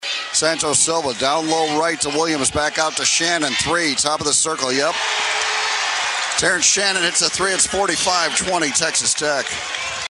with the call on the Cyclone Network. 14th ranked Texas Tech raced out to a 19 point halftime lead and crushed the Cyclones 72-41 in the quarterfinal round of the Big 12 Tournament.